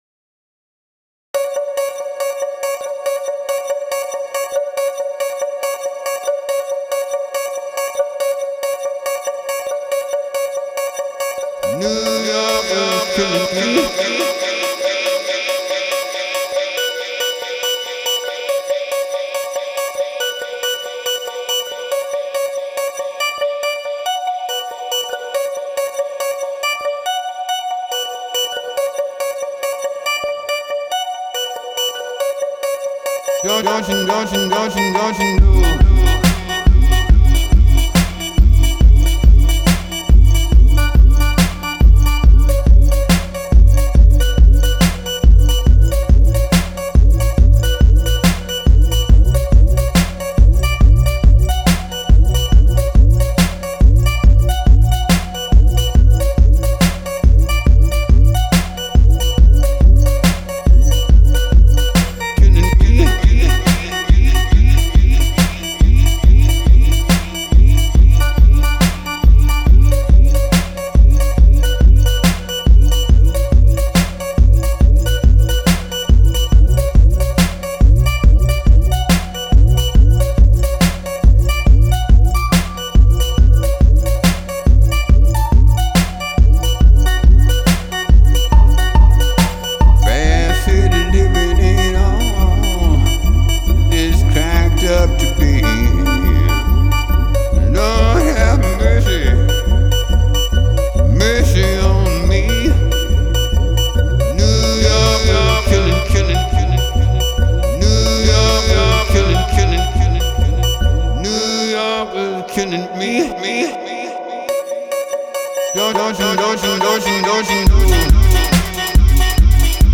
jazz/blues/soul musician